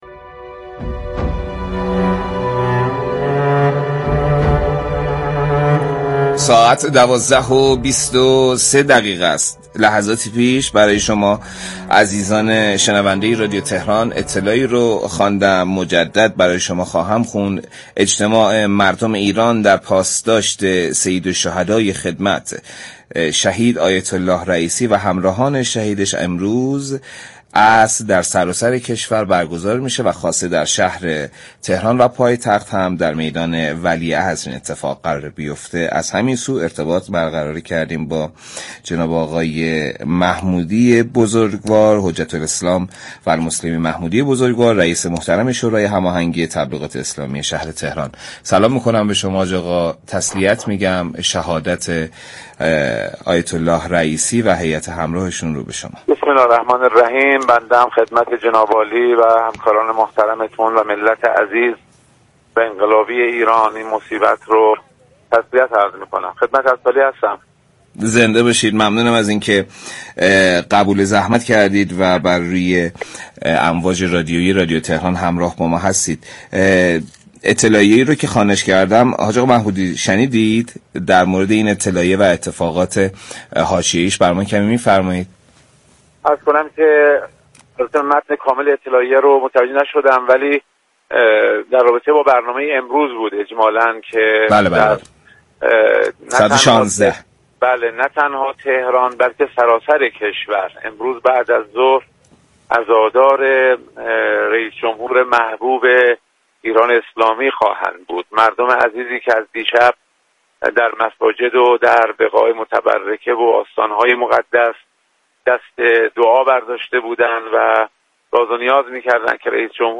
در گفت و گو با ویژه برنامه «شهید جمهور»